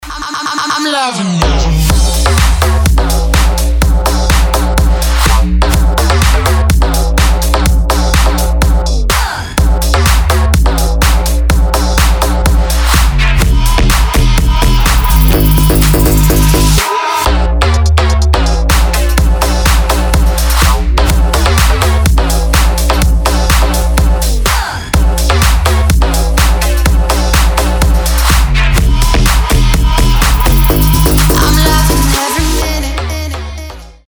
• Качество: 320, Stereo
громкие
мощные
женский вокал
Electronic
EDM
future house
Bass House
Стиль: bass/ future house